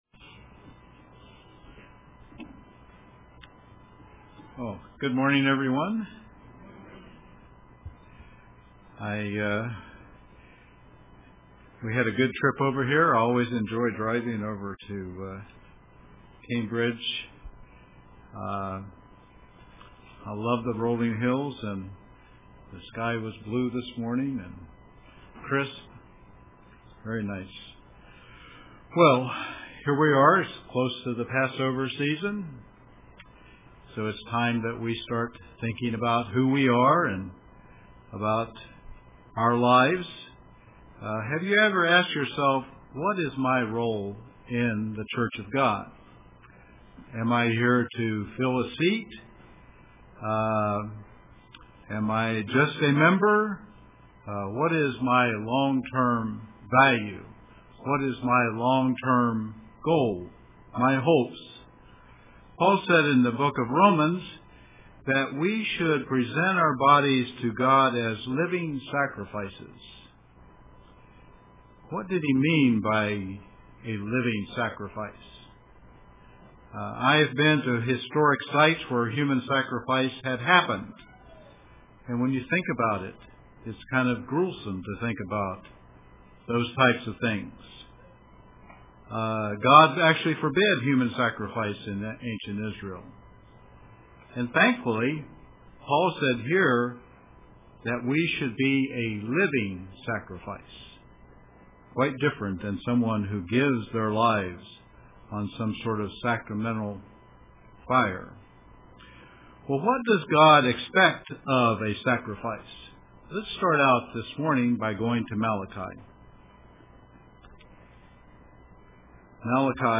A Living Sacrifice UCG Sermon Studying the bible?